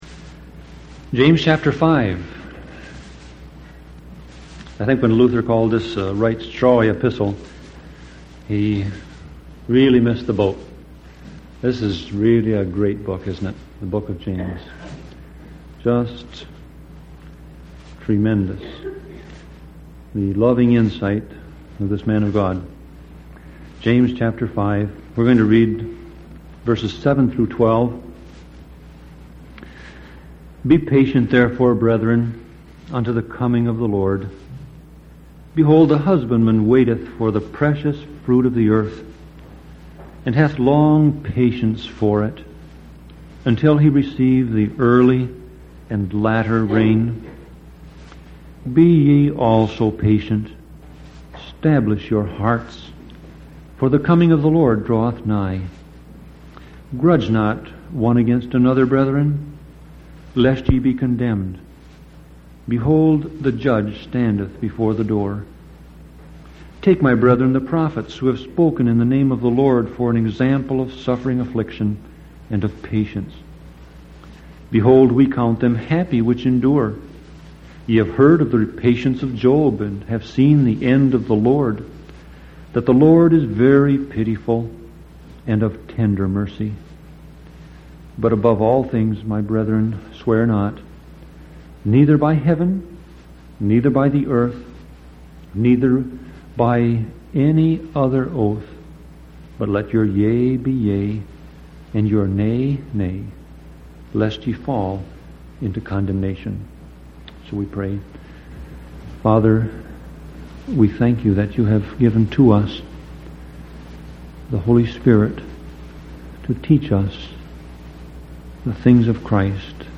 Sermon Audio Passage: James 5:7-12 Service Type